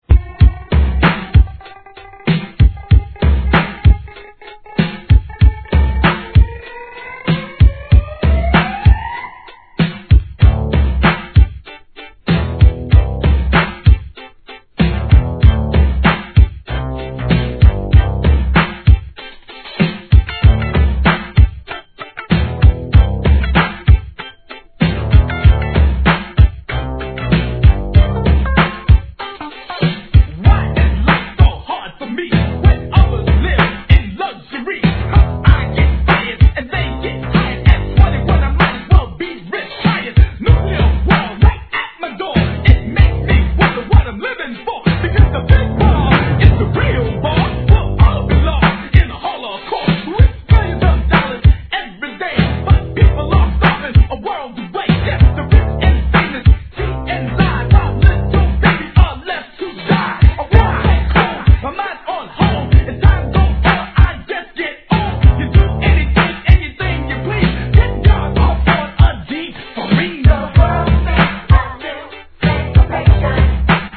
¥ 880 税込 関連カテゴリ SOUL/FUNK/etc...